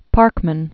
(pärkmən), Francis 1823-1893.